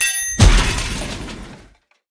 wrench_hit_part_01.ogg